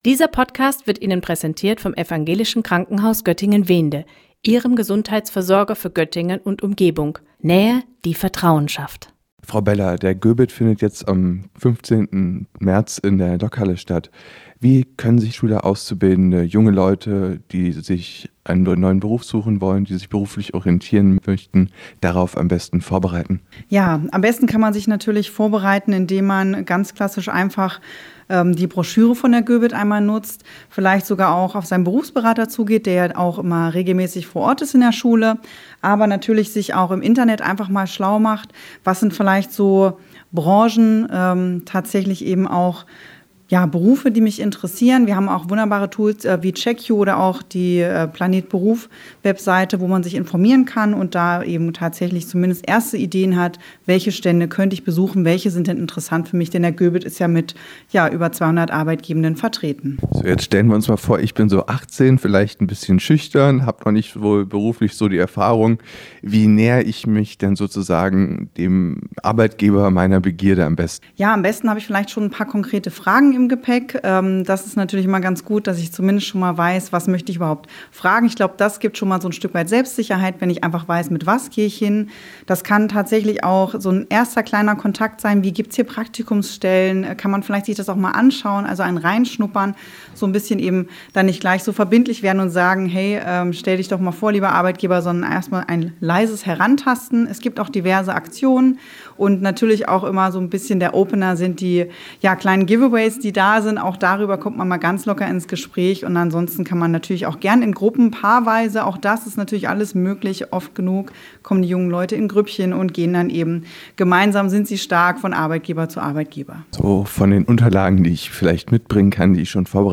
Am 15. März findet der GöBit, der Göttinger Berufsinfotag, wieder in der Lokhalle statt. Im Gespräch